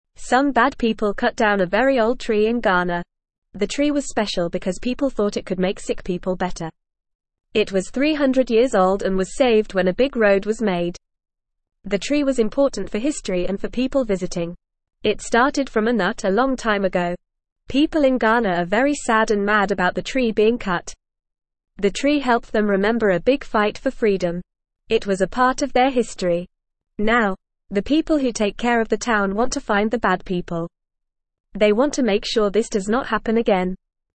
Fast
English-Newsroom-Beginner-FAST-Reading-Old-Tree-in-Ghana-Cut-Down-by-Bad-People.mp3